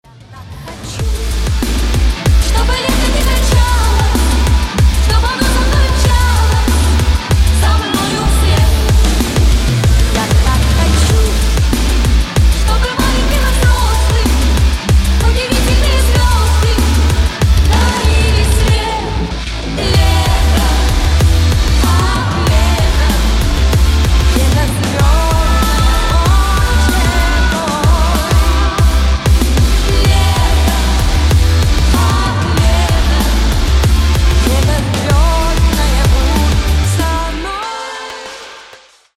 • Качество: 128, Stereo
retromix